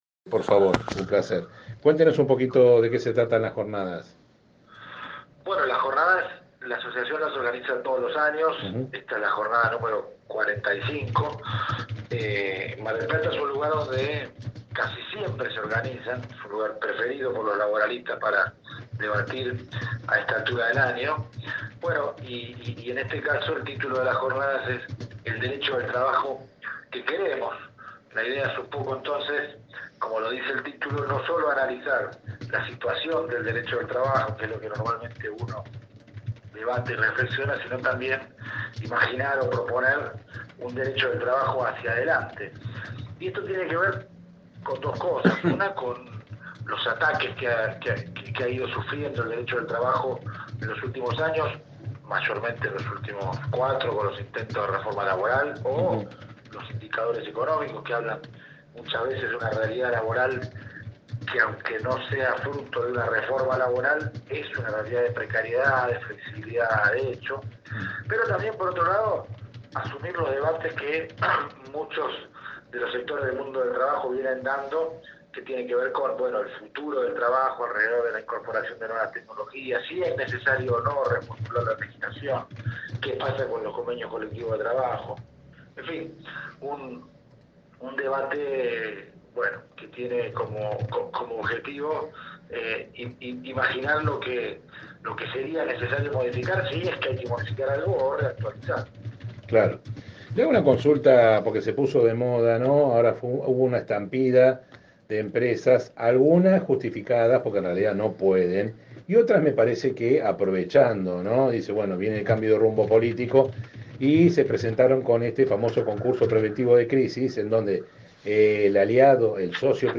programa emitido de 7 a 9, por radio De la Azotea